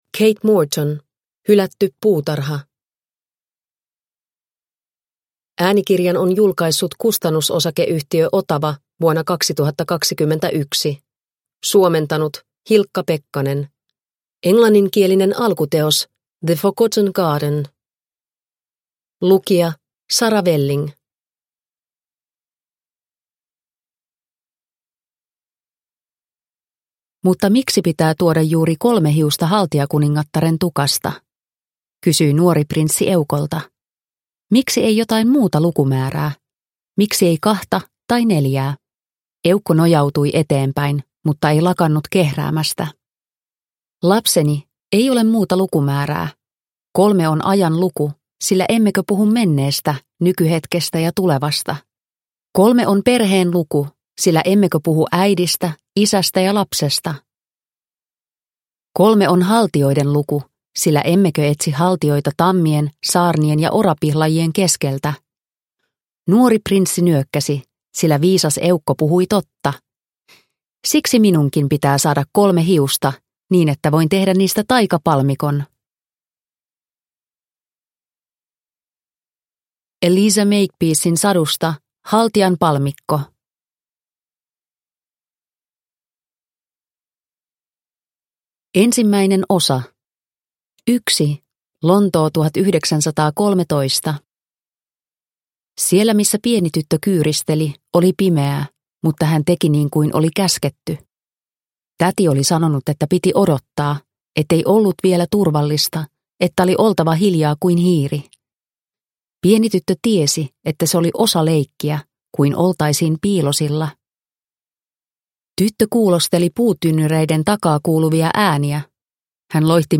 Hylätty puutarha – Ljudbok – Laddas ner